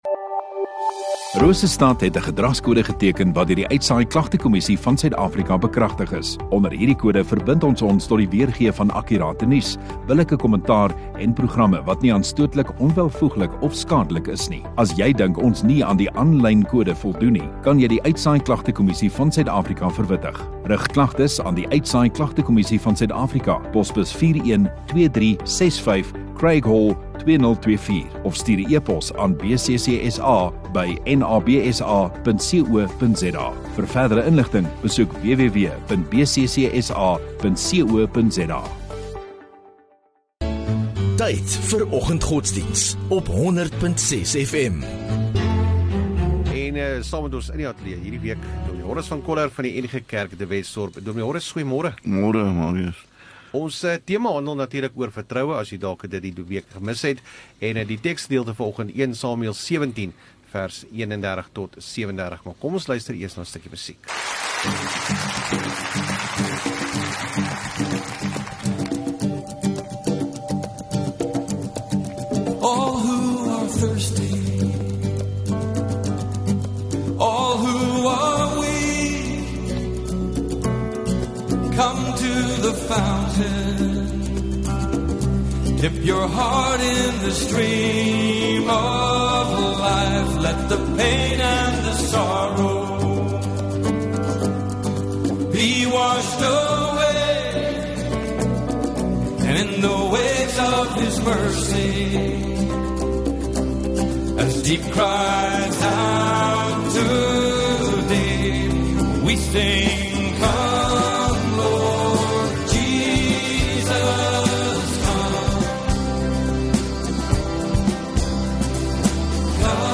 21 Nov Donderdag Oggenddiens